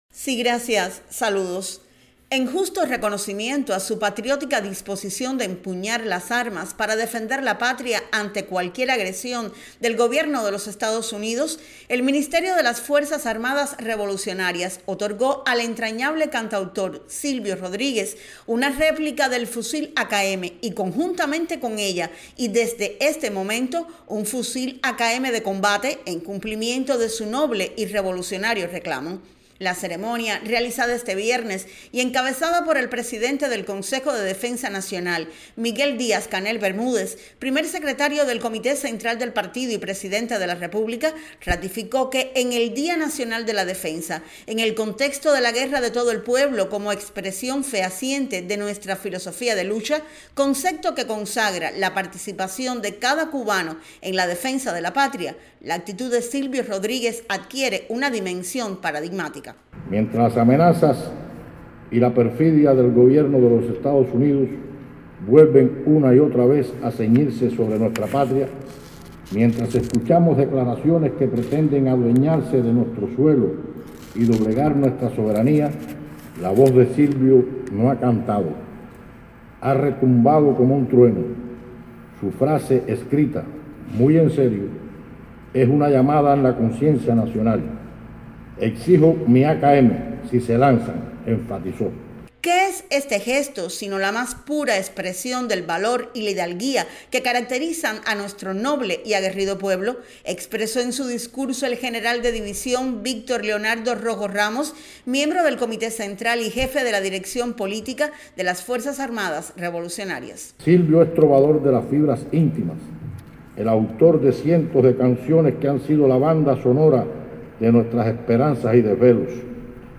Este viernes en la mañana, en la sede del Ministerio de las Fuerzas Armadas Revolucionarias y ante la presencia del Presidente del Consejo de Defensa Nacional, Miguel Díaz-Canel Bermúdez y de otros dirigentes de la Revolución, el poeta y cantautor Silvio Rodríguez recibió una réplica del fusil AKM, y otro de combate.